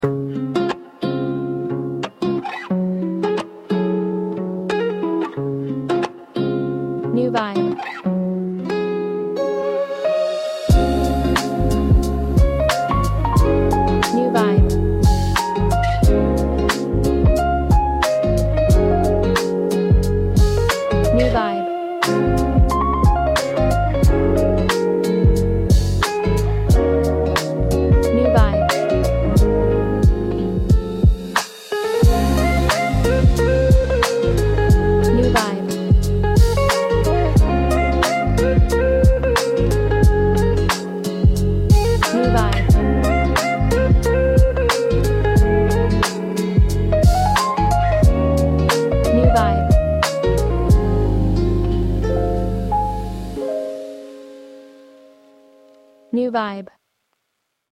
Genre: Funky, piano